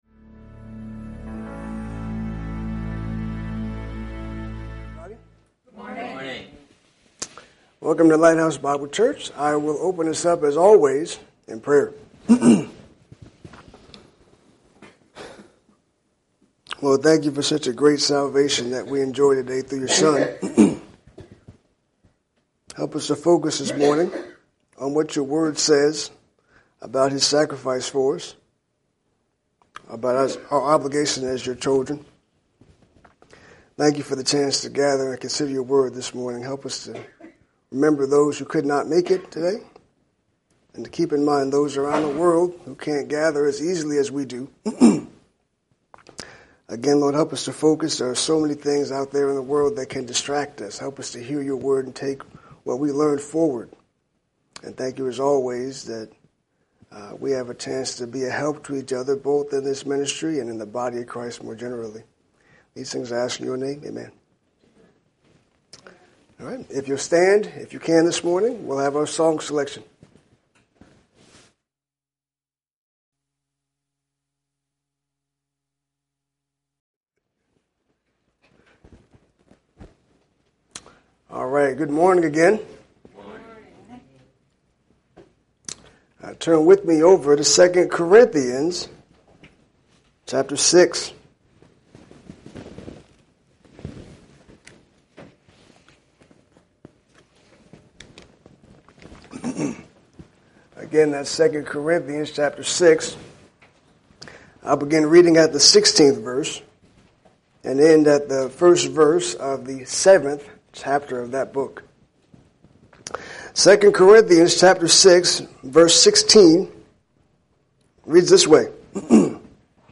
Lighthouse Bible Church (LBC) is a no-nonsense, non-denominational, grace oriented and Bible centered Christian church.